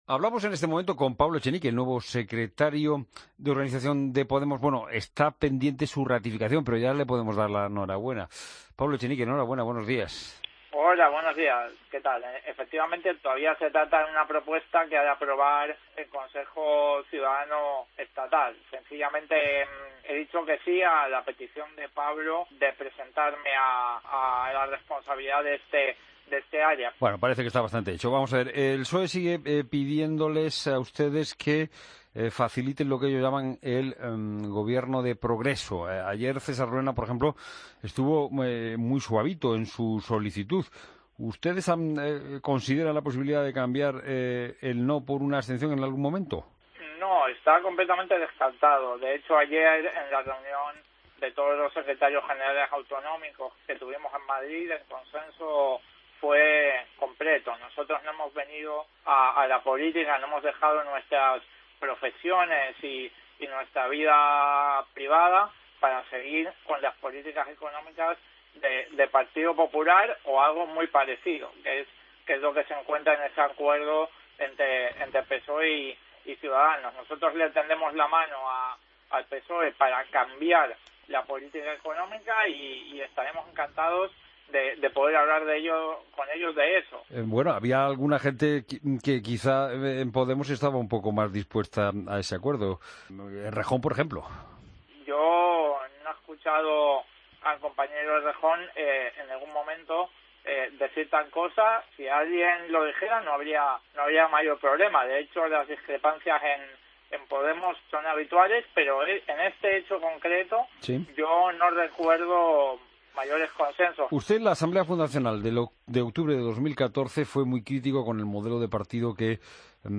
AUDIO: Escucha la entrevista a Pablo Echenique (Podemos) en La Mañana de Fin de Semana de COPE